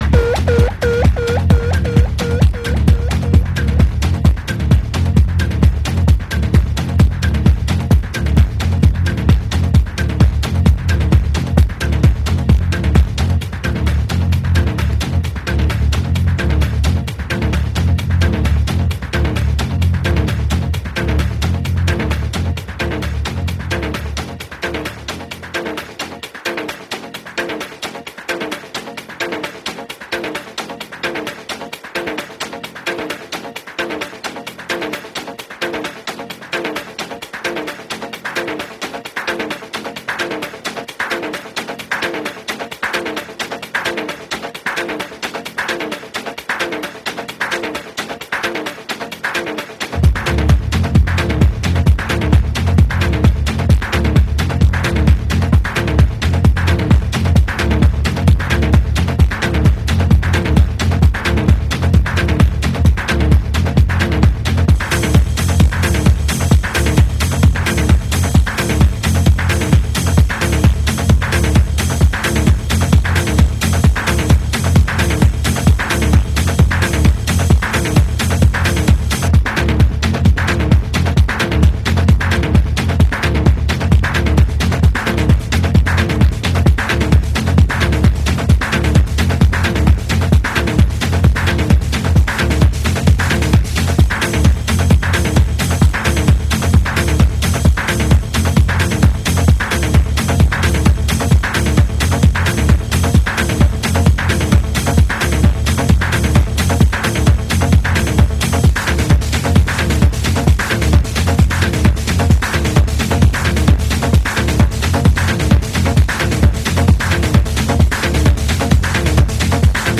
Nem döngölésről szól, inkább egy okosan összeállított anyag.